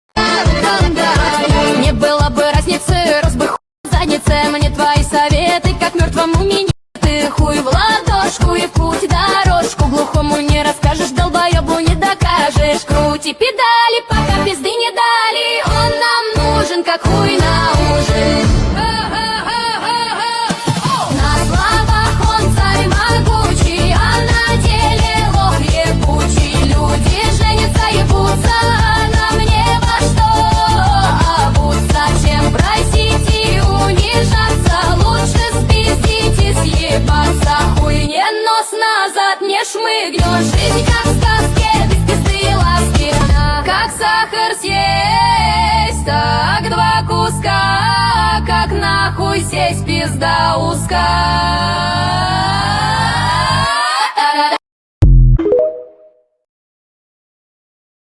Тик ток Ремикс нейросеть 2025